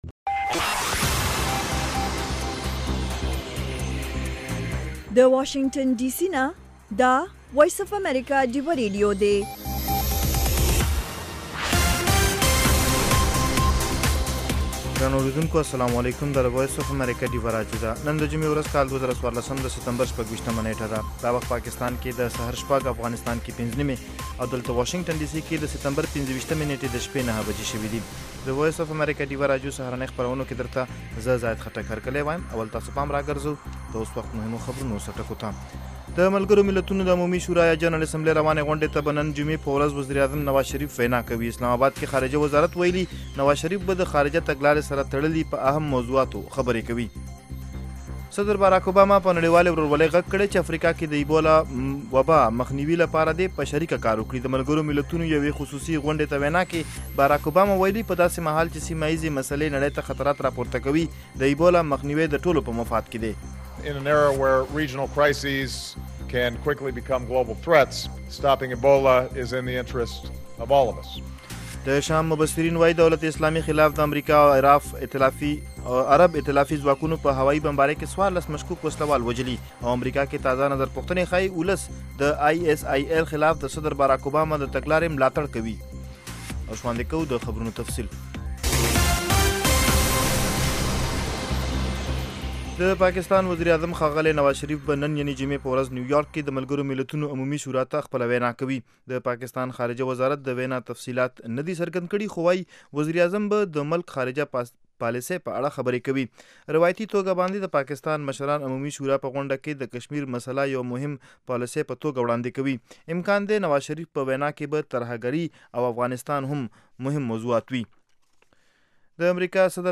خبرونه - 0100